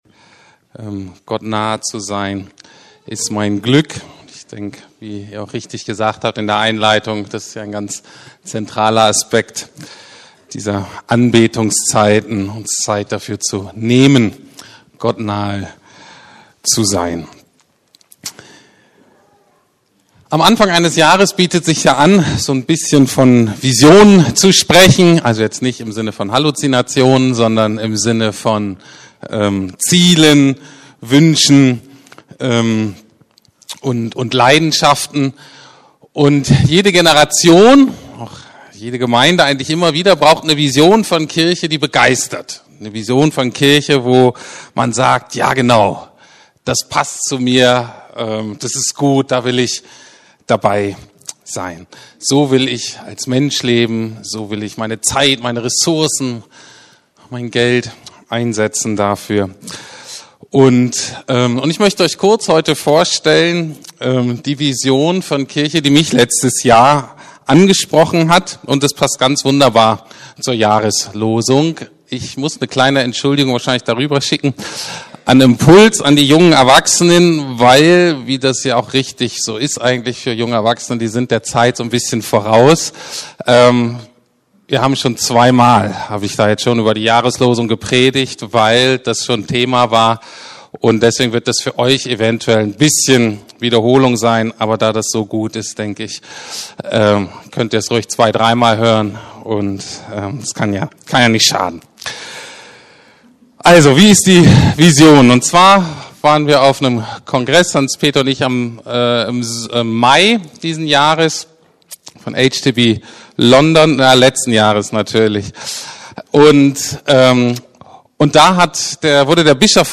Neujahrsgottesdienst ~ Predigten der LUKAS GEMEINDE Podcast
Neujahrsgottesdienst